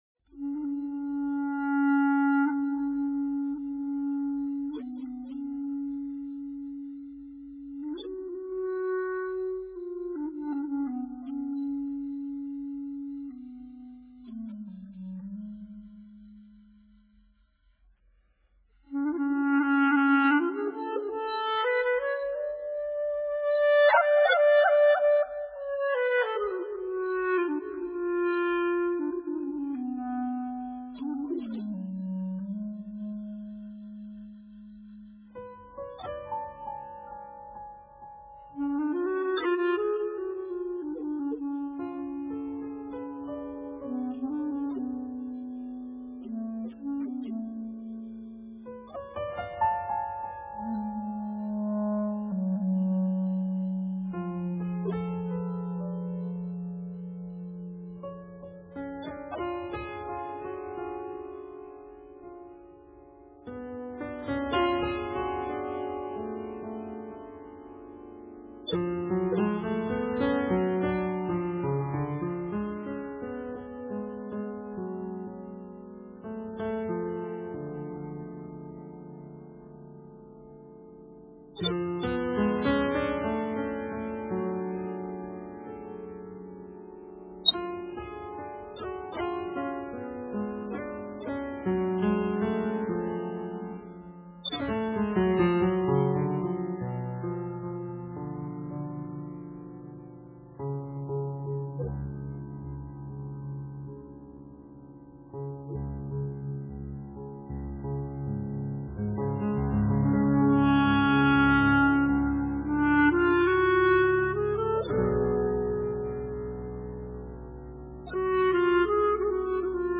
oriental space jazz